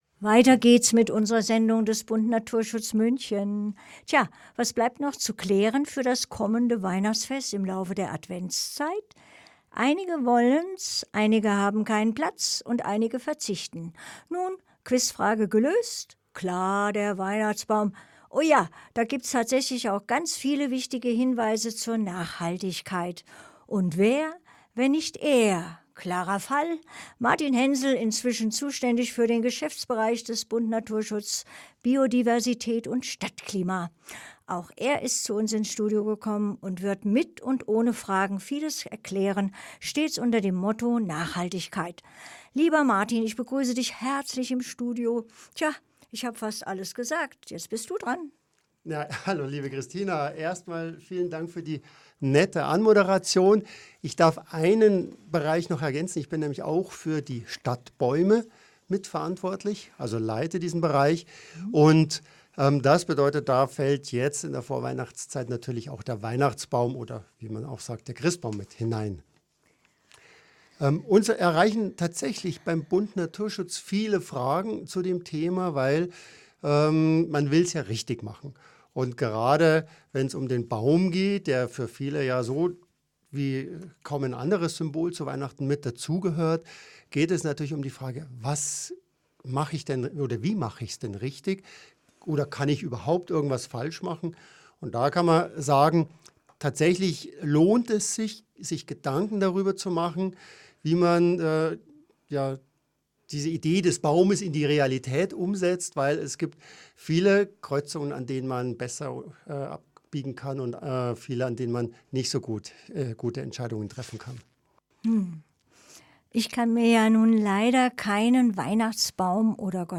Interview 3